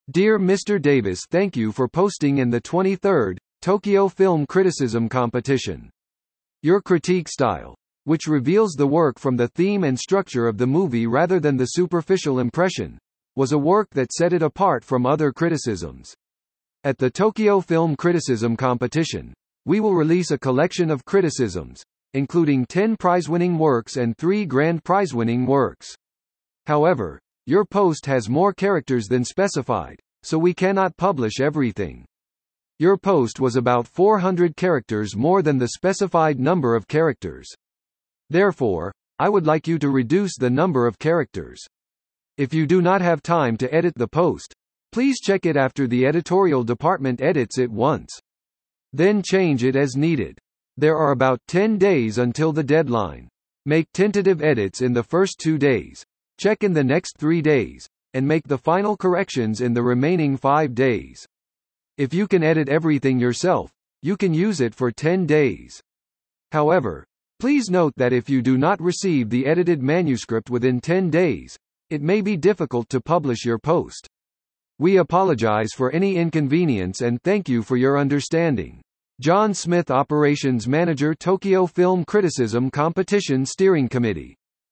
本文読み上げ